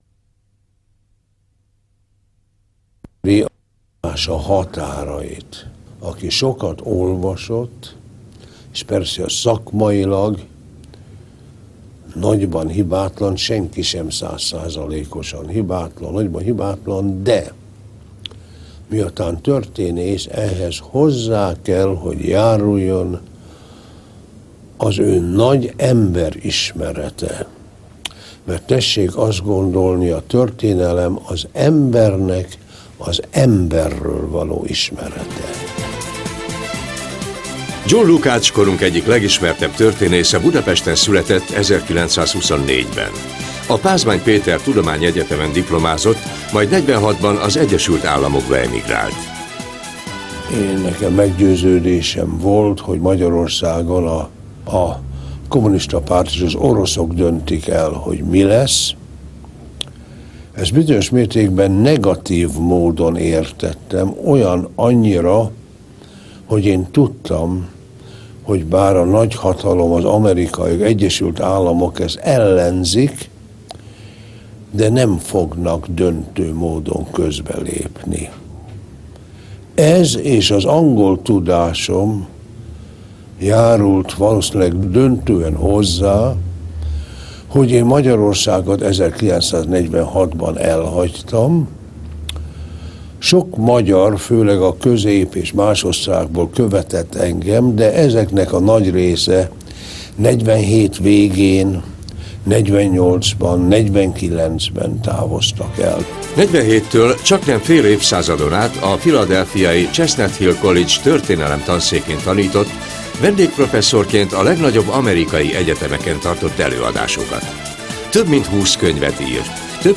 John Lukács (interviewee)